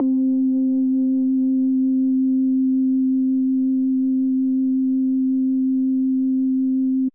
808 Bass.wav